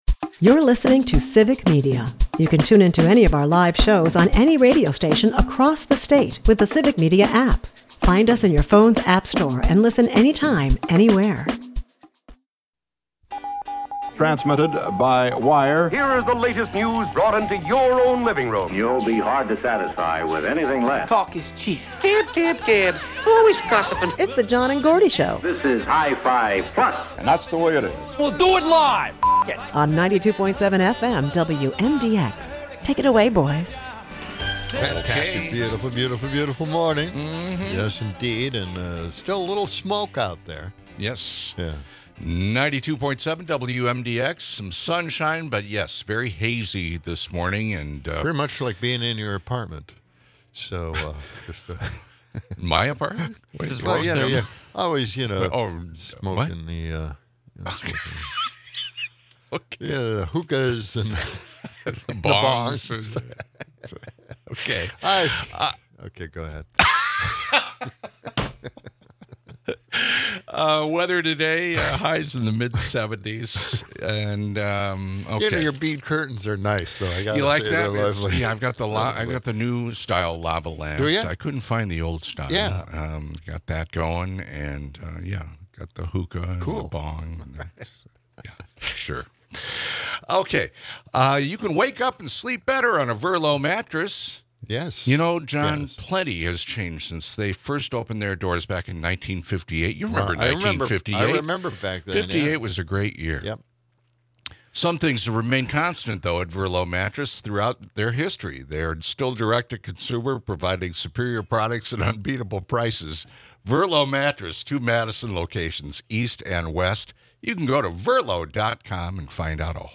Trump's baffling tirade against wind turbines is hilariously dissected, with scary music underneath!